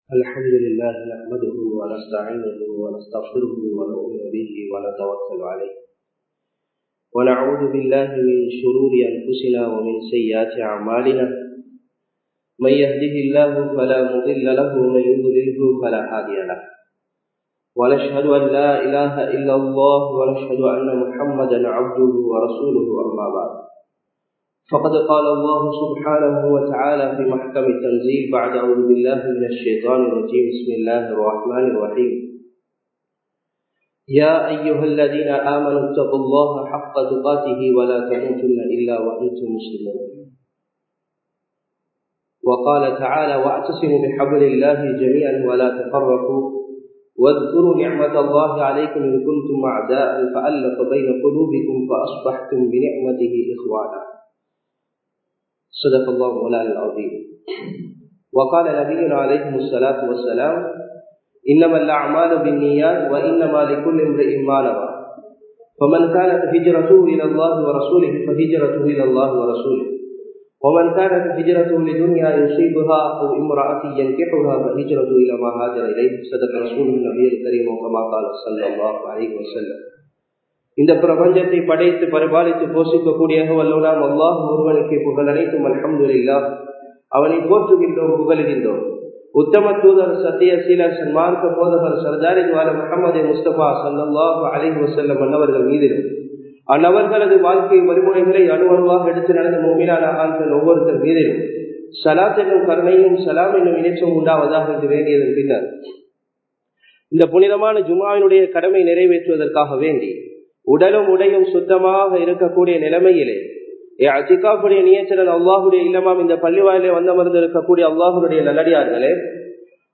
அல்லாஹ்வை அஞ்சுவோம் (Lets keep fear of allah) | Audio Bayans | All Ceylon Muslim Youth Community | Addalaichenai
Muhiyadeen Jumua Masjidh